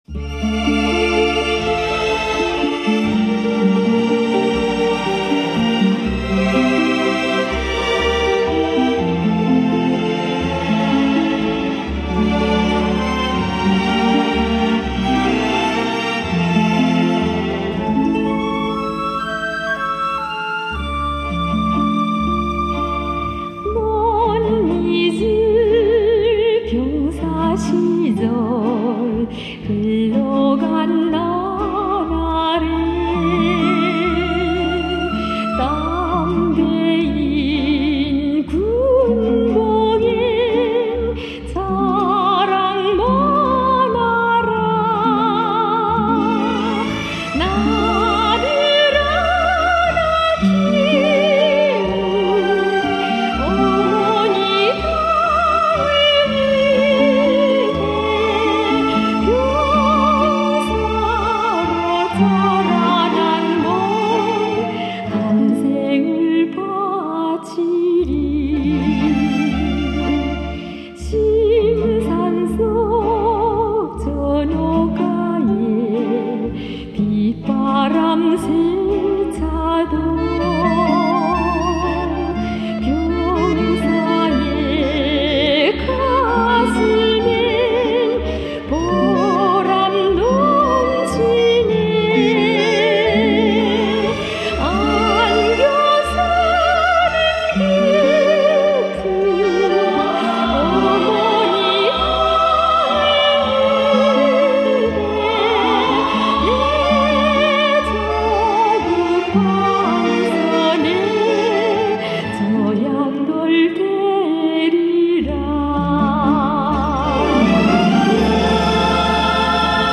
[21/4/2009]魅力无穷朝鲜艺术歌曲